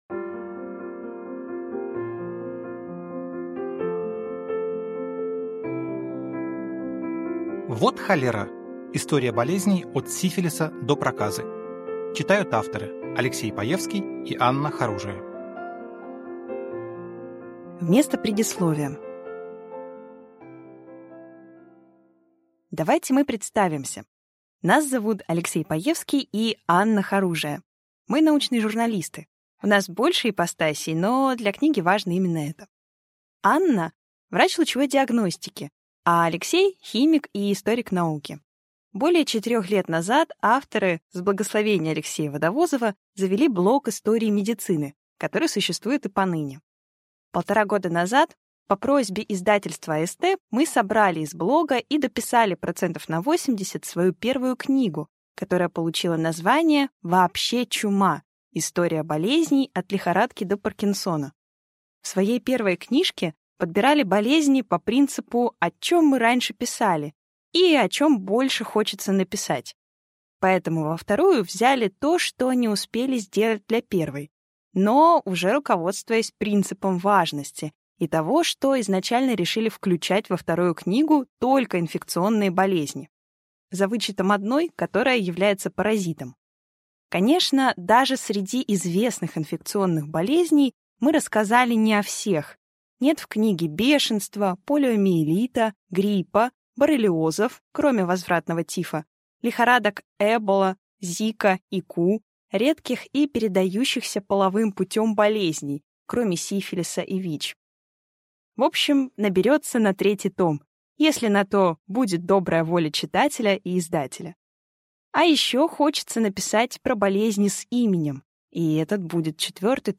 Аудиокнига Вот холера! История болезней от сифилиса до проказы | Библиотека аудиокниг